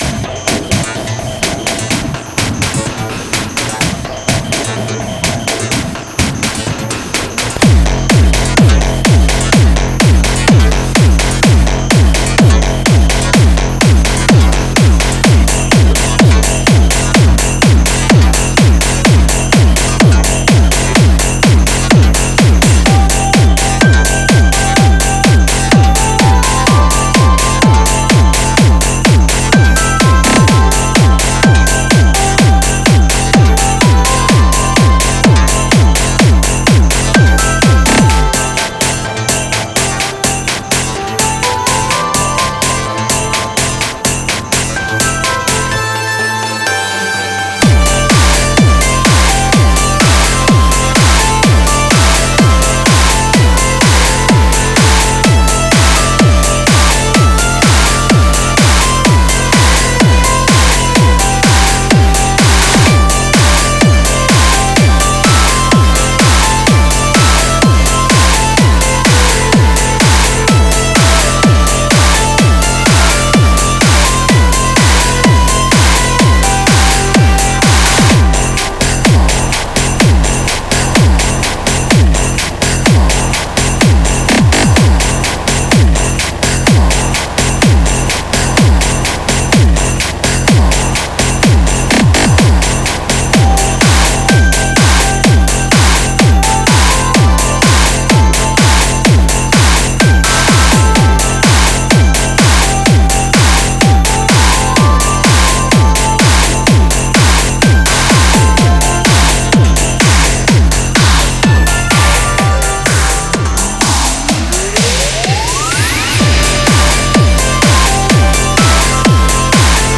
Industrial, Rhythmic Noise, xmas, Christmas